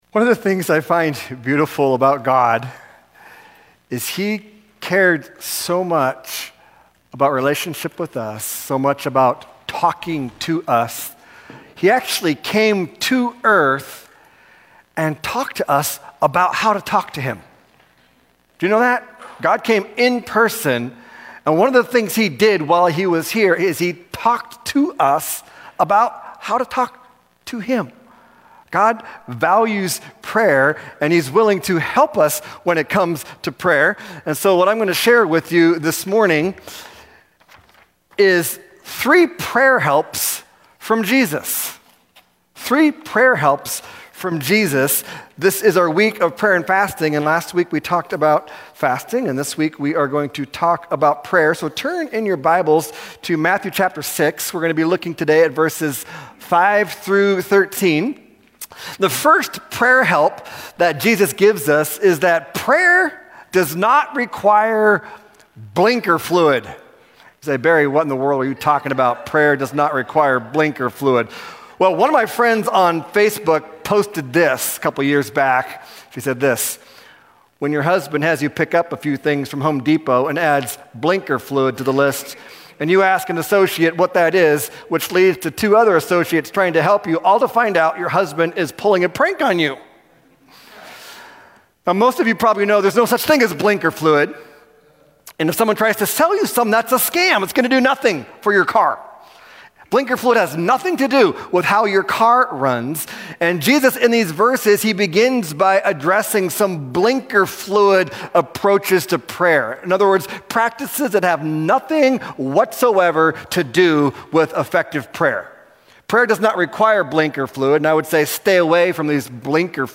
This sermon is from our 'Year in Preview' Service